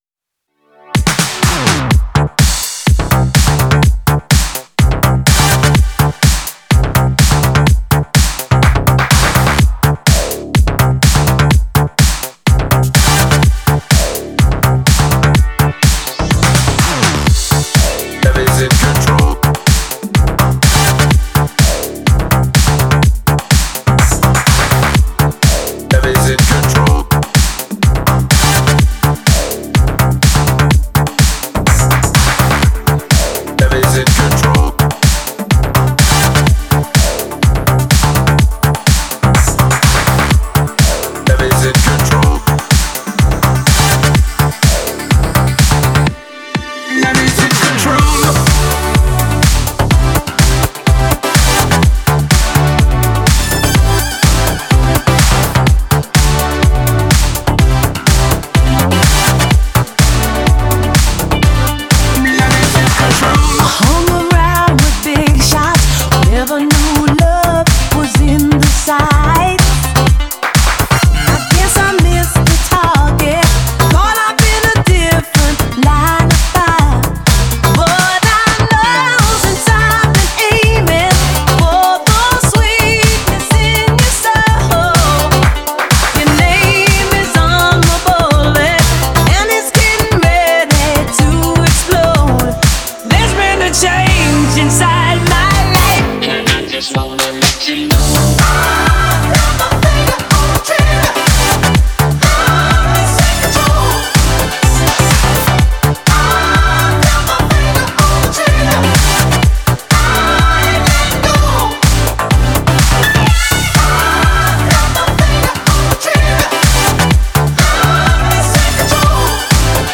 Genre: Dance, Disco, Nu-Disco, Funk